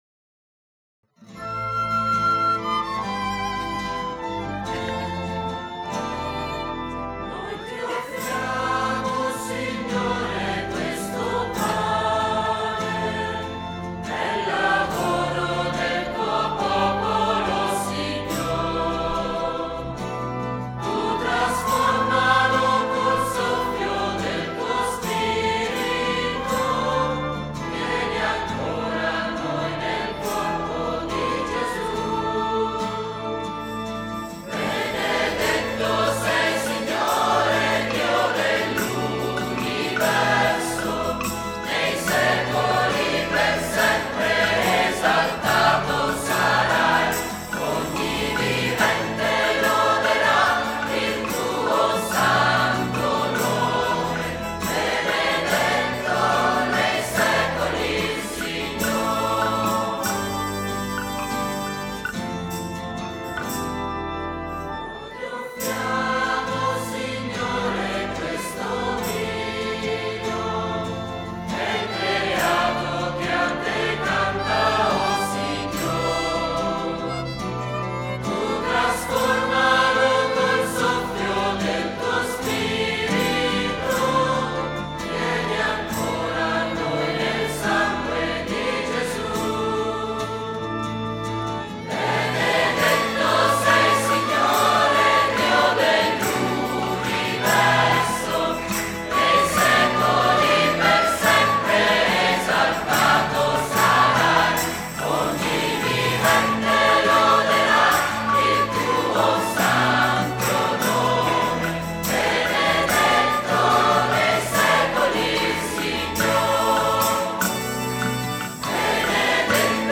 Organo
Violino
Chitarra
Percussioni
Soprani
Contralti
Tenori
Bassi